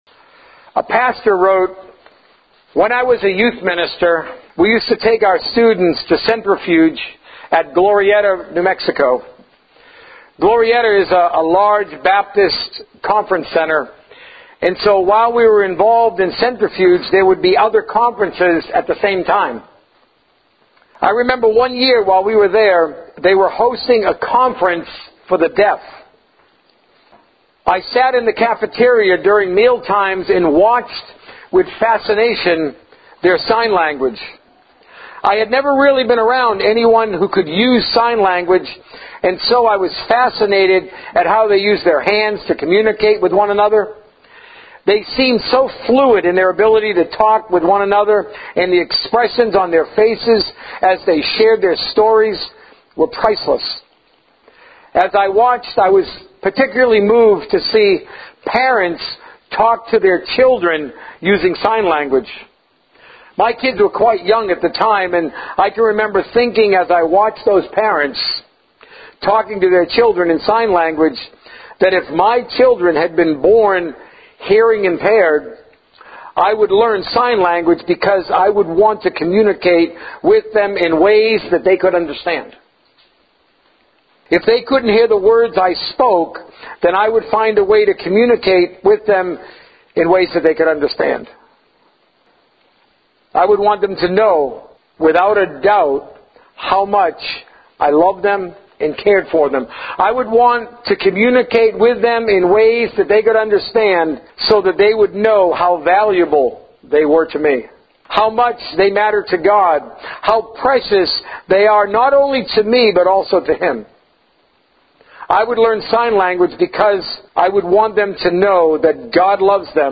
Sermons focused on the birth of our Savior Jesus Christ.